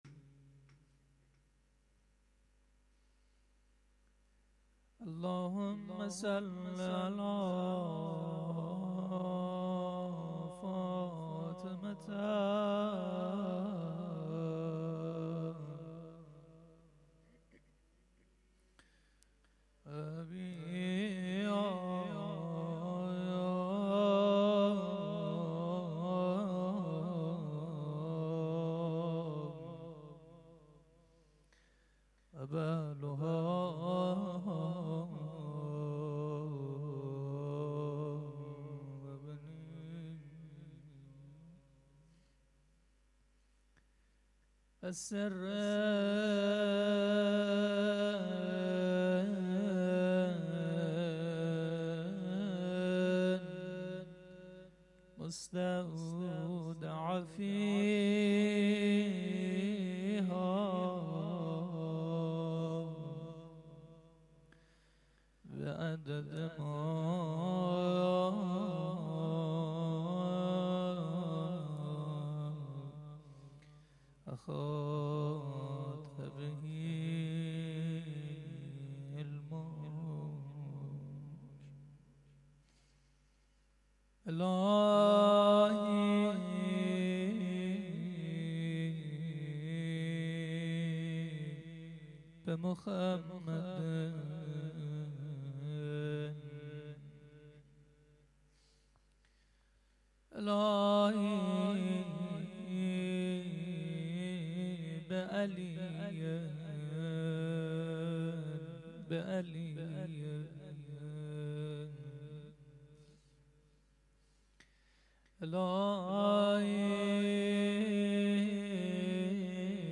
هفتگی مناجات 93.3.13(1).mp3